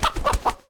combat / creatures / chicken / he / hurt2.ogg
hurt2.ogg